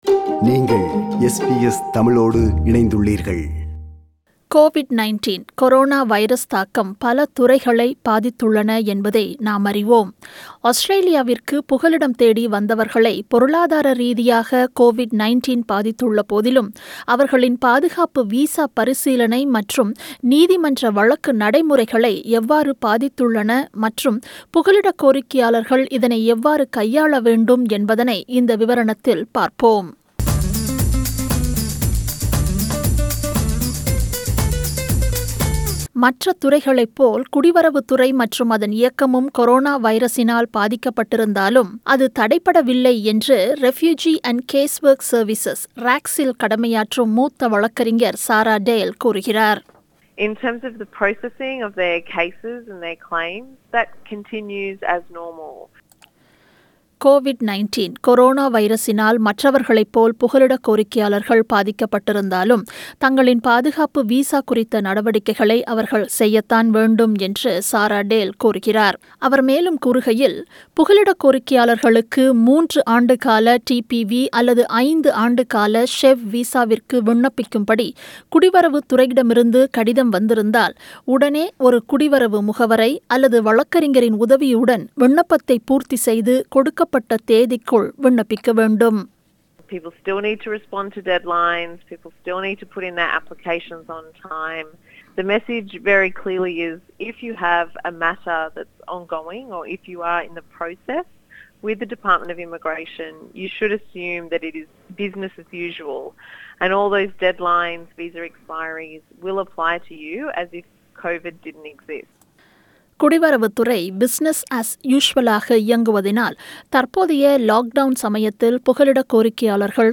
புகலிட கோரிக்கையாளர்களின் பாதுகாப்பு வீசா பரிசீலனை நடைமுறையில் கொரோனா வைரஸ் தொற்று பரவல் ஏற்படுத்தியுள்ள தாக்கம் மற்றும் அதனை புகலிடக்கோரிக்கையாளர்கள் எவ்வாறு கையாள வேண்டும் என்பதனை விளக்கும் விவரணம்.